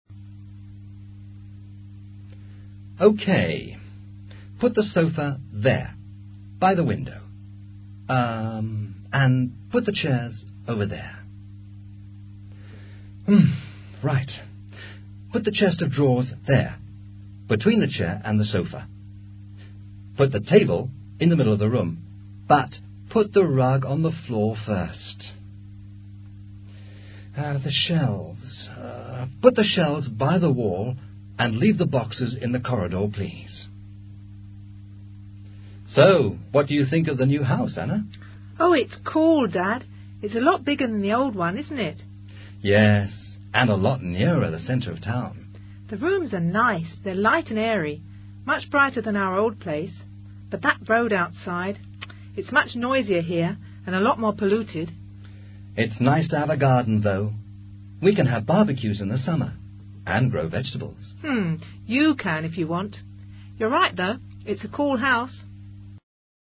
Diálogo entre un padre y su hija durante una mudanza.